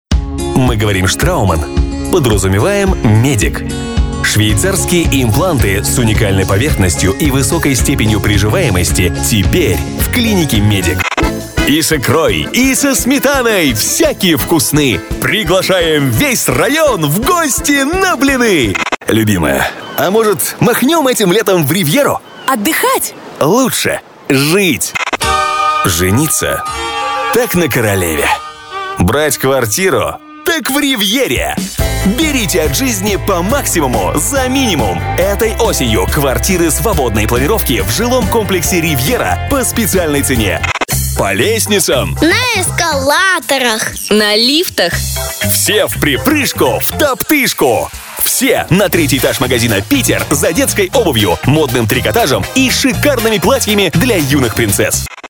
Разноплановый диктор.
Тракт: AKG414, Rode NTK -> WarmerSound MC41, dbx376 -> TC Impact Twin